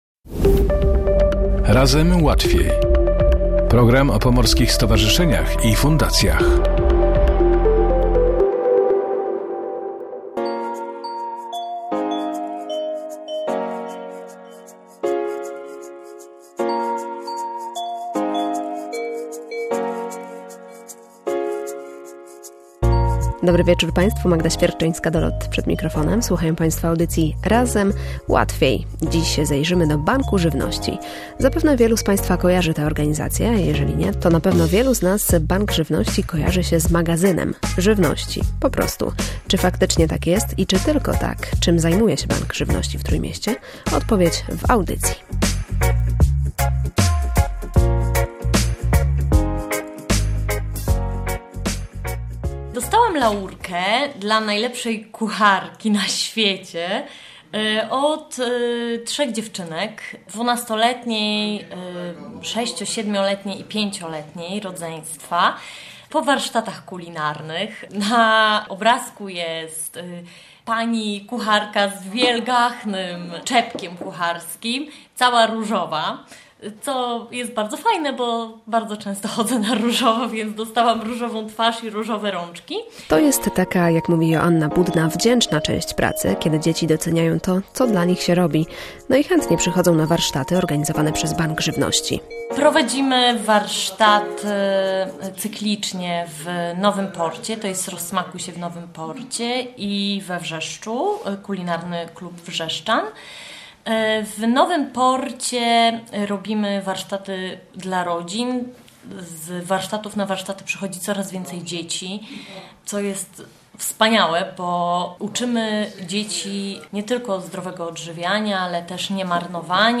O tym jak kupować, by nie marnować, opowiadają wolontariusze i pracownicy Banku Żywności w Trójmieście.